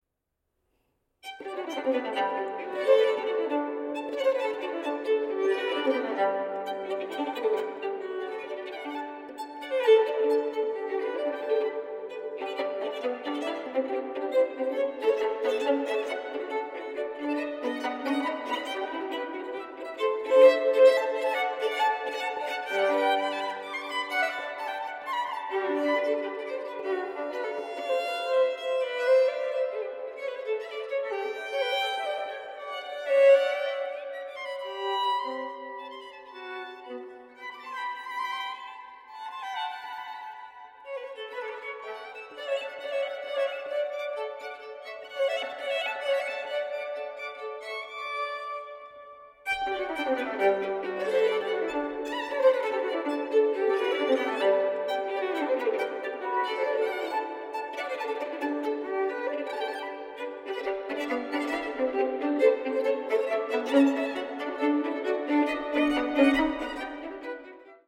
• Genres: Baroque, Classical, Strings